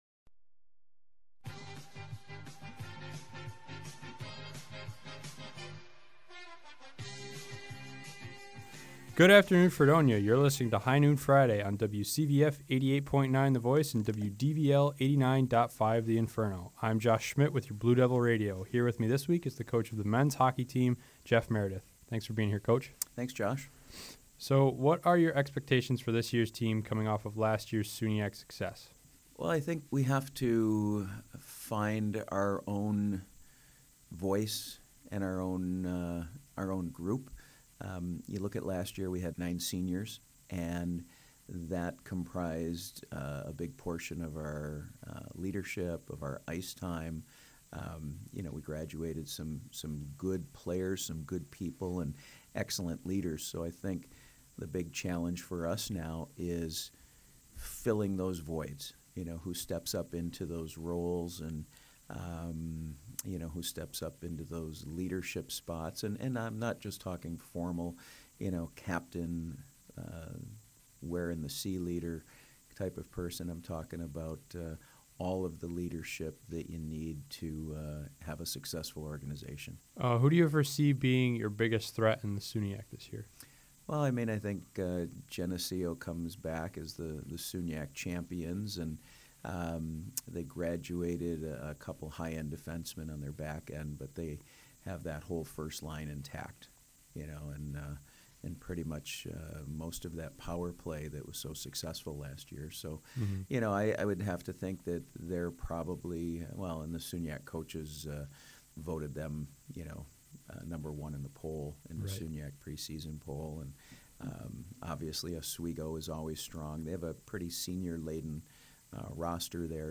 HNF_Hockey_Interview_mp3.mp3